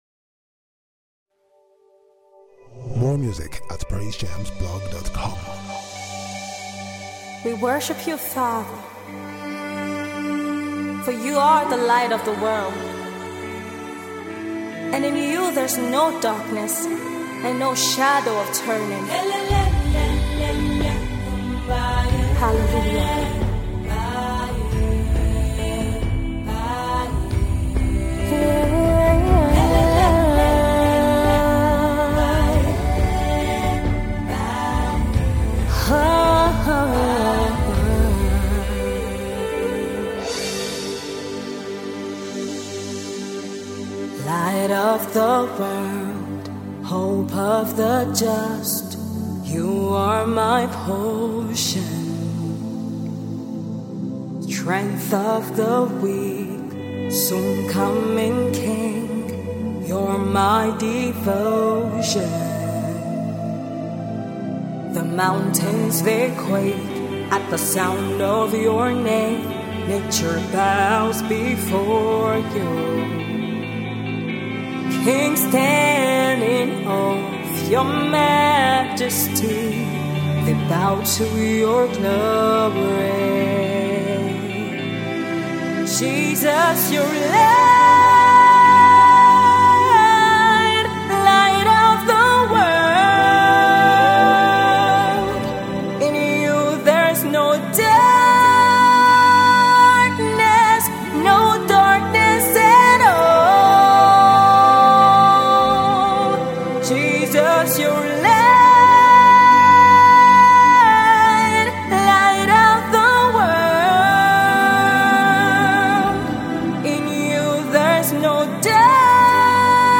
Nigerian exquisite gospel music minister and songwriter